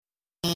Alphys Talking